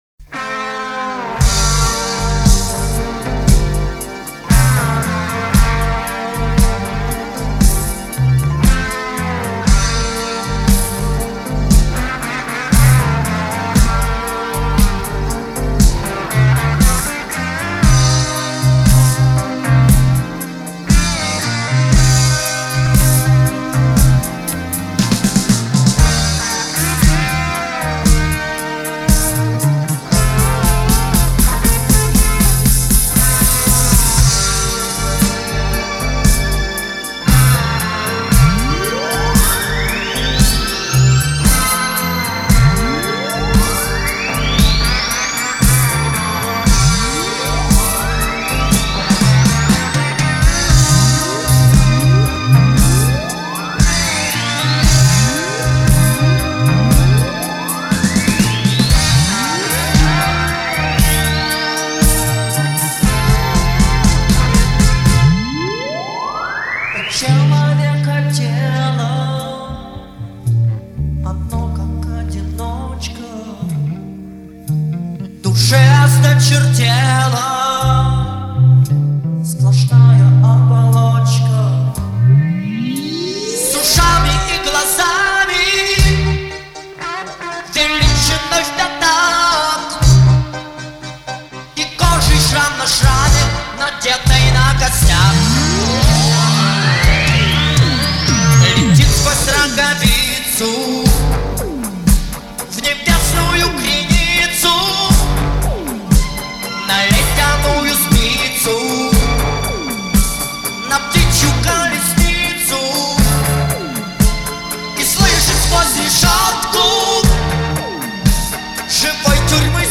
арт-рок.